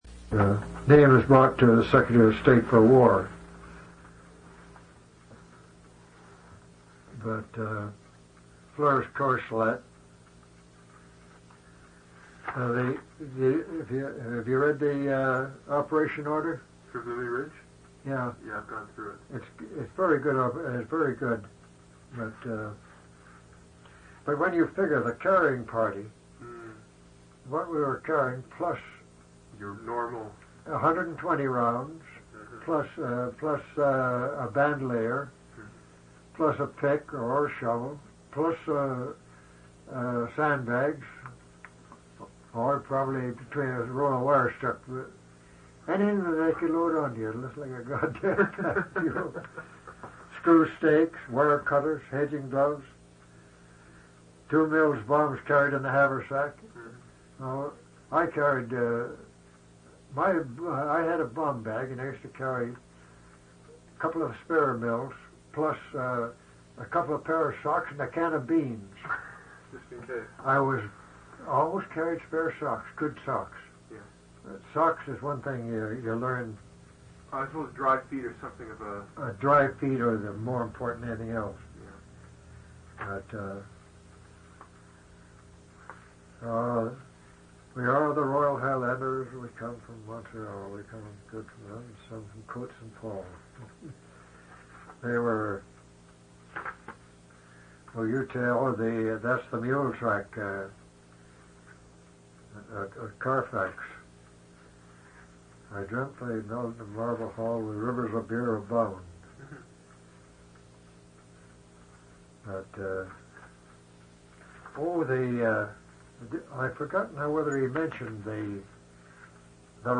Canadian Military Oral Histories